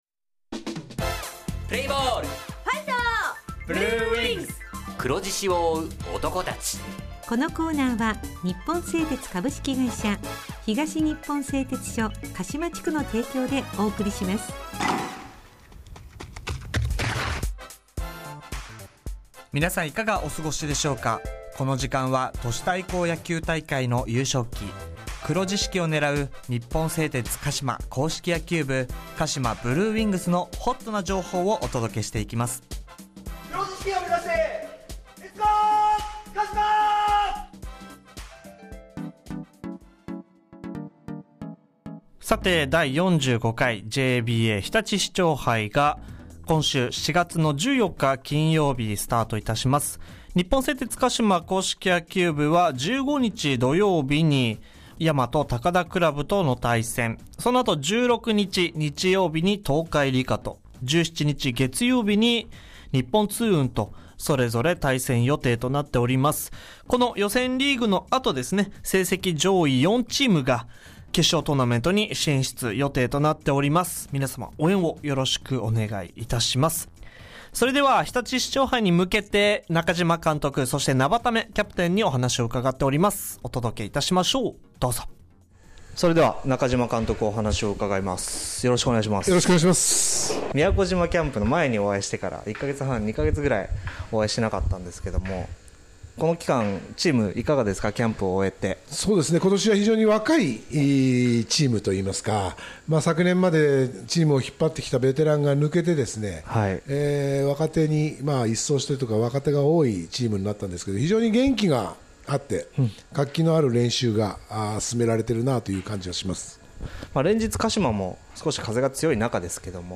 地元ＦＭ放送局「エフエムかしま」にて当所硬式野球部の番組放送しています。